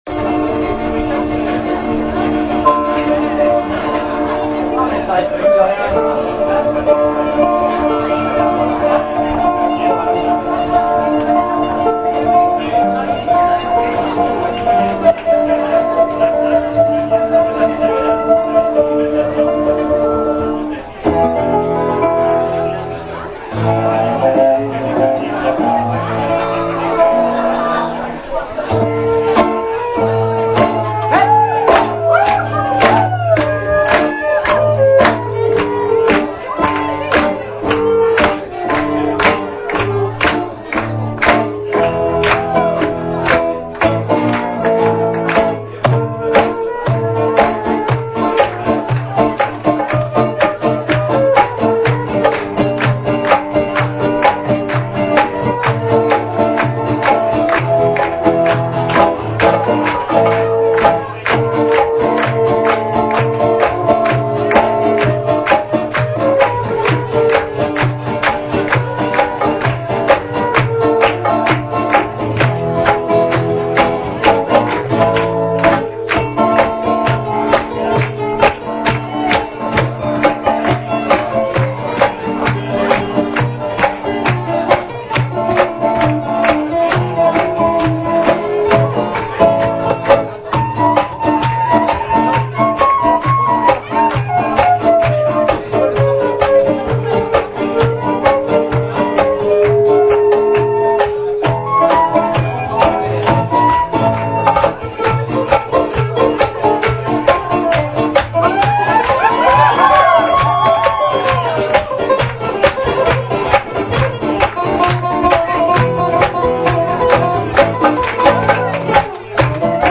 ! Extraits du concert au format mp3 !